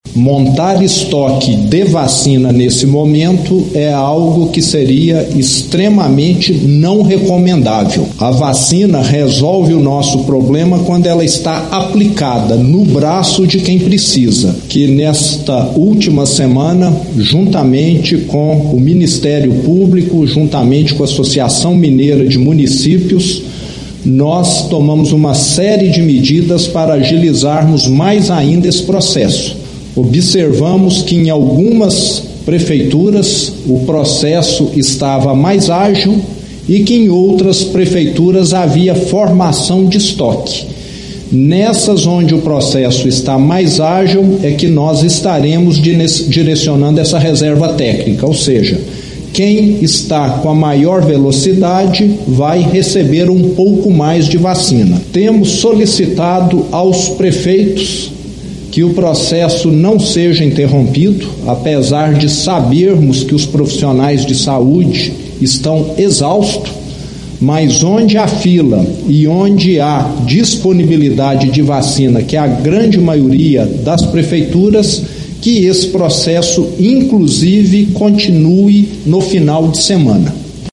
As declarações foram feitas pelo governador Romeu Zema, em coletiva na manhã desta quinta-feira, 8, na qual ele apresentou informações sobre a situação da pandemia no estado.